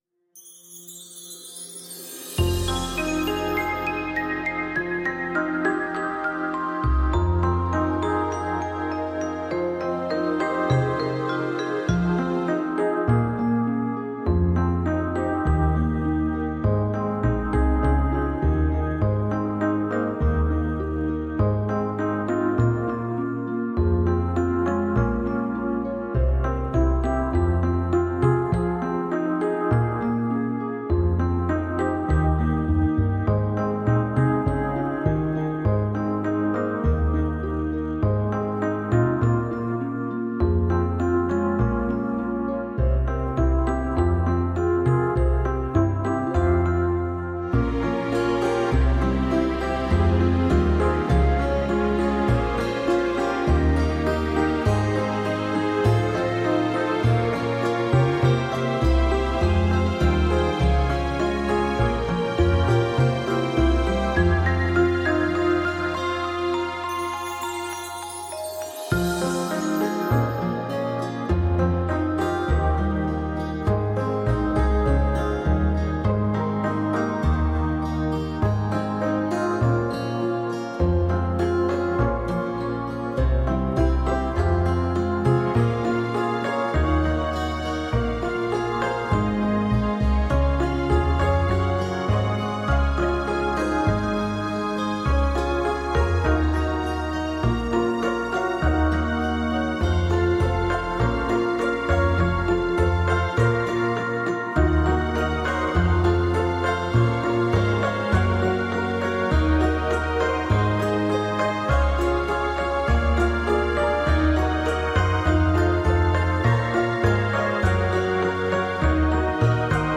ГлавнаяПесниПесни колыбельные